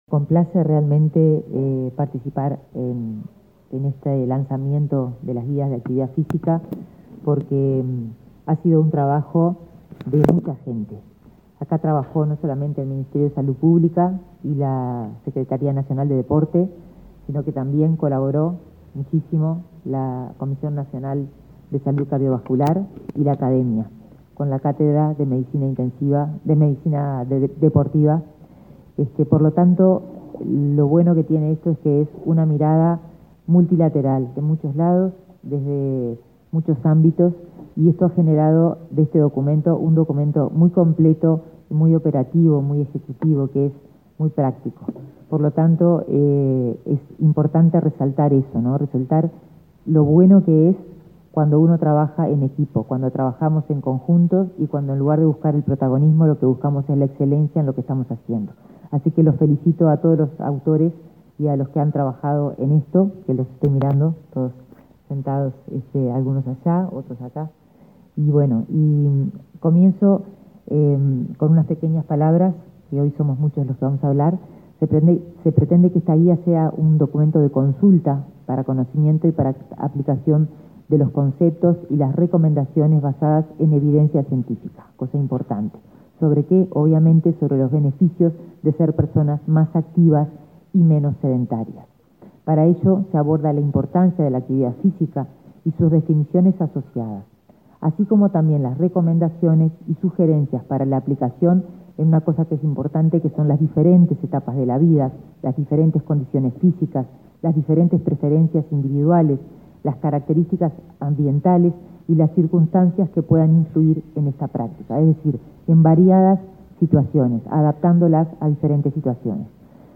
Palabras de autoridades en el MSP